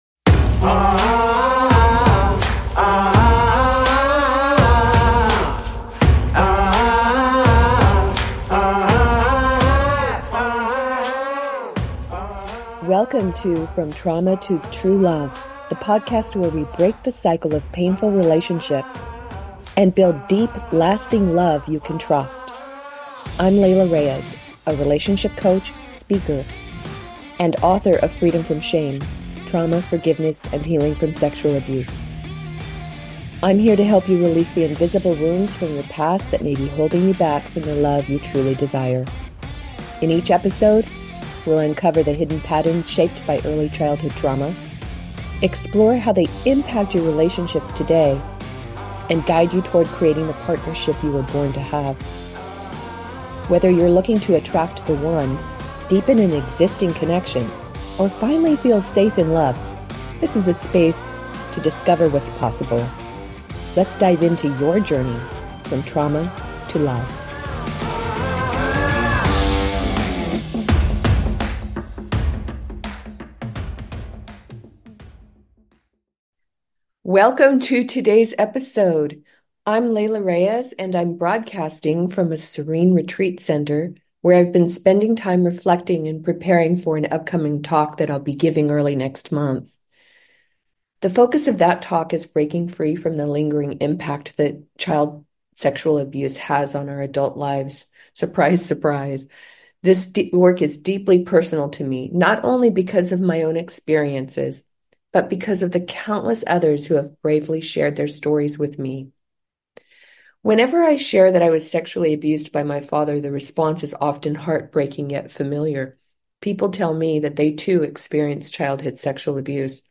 Talk Show Episode, Audio Podcast, From Trauma To True Love and S1E10, Sibling Sexual Abuse Research on , show guests , about Sibling Sexual Abuse Research, categorized as Health & Lifestyle,Love & Relationships,Relationship Counseling,Psychology,Emotional Health and Freedom,Mental Health,Personal Development,Self Help,Society and Culture